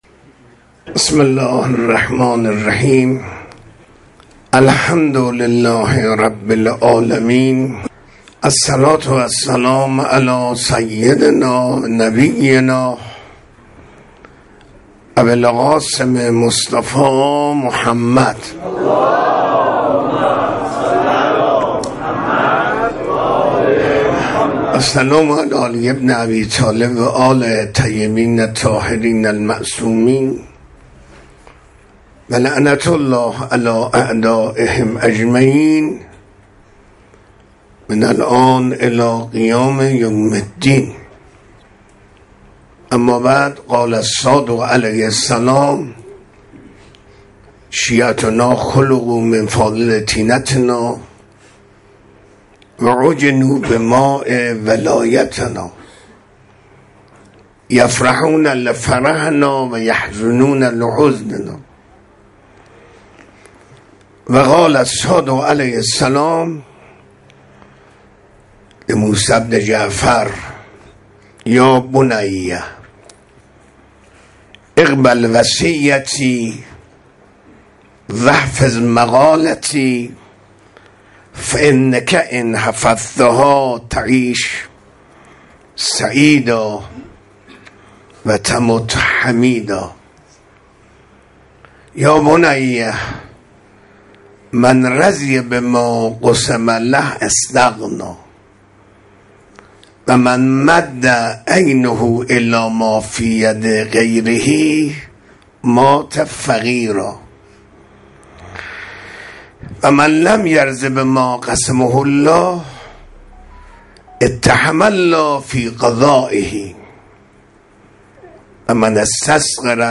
منبر